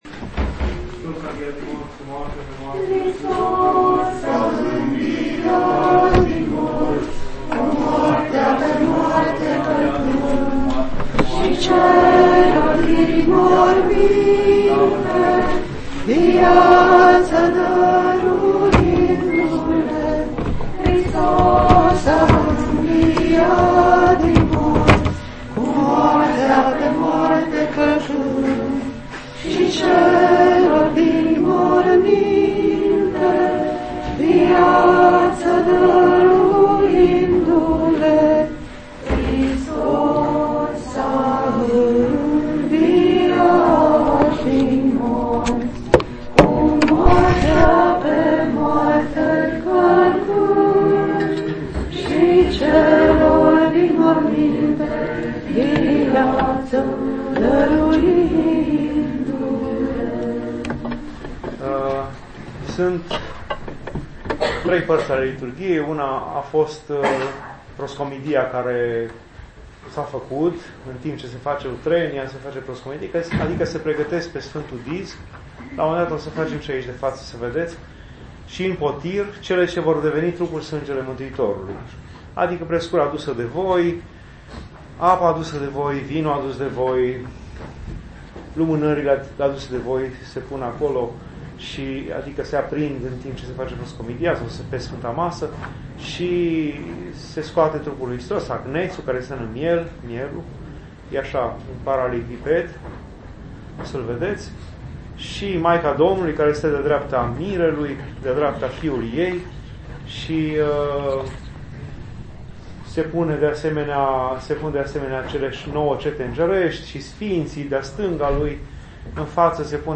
conf. a avut loc la Aula Magna a Fac. de Teologie